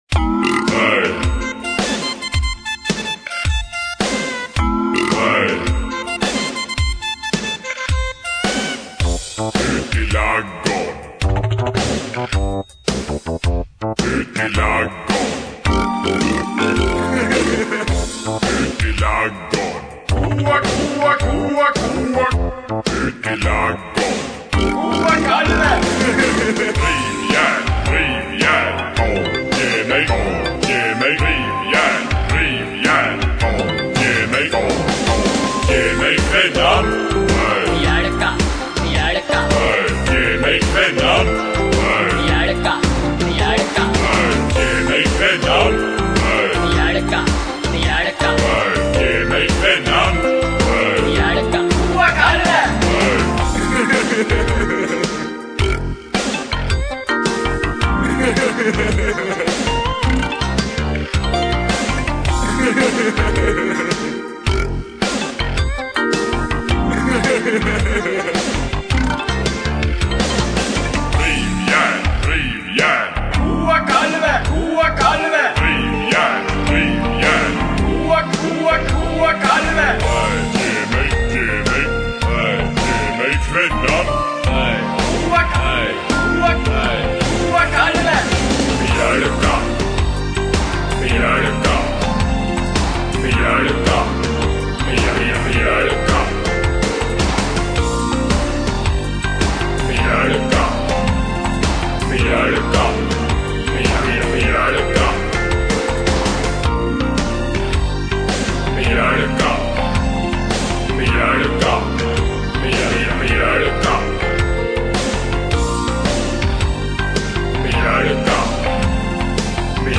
Lo-Fi